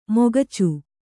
♪ mogacu